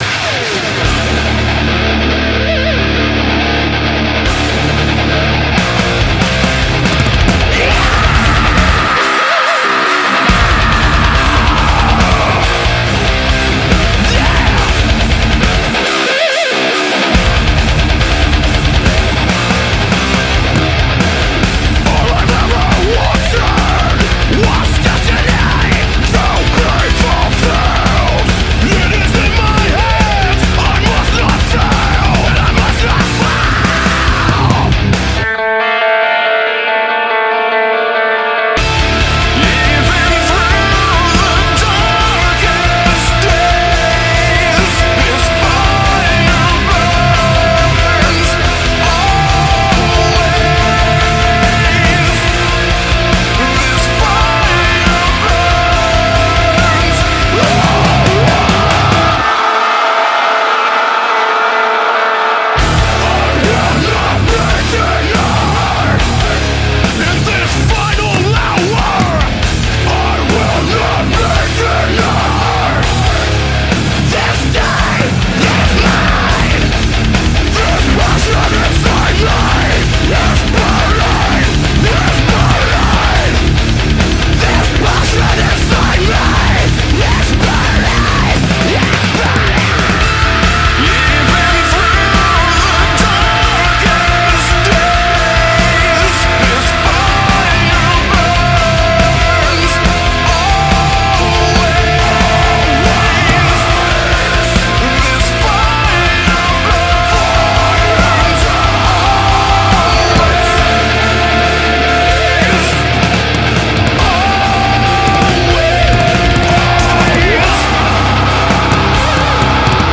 BPM83-140
Audio QualityPerfect (High Quality)
• a really weird slowdown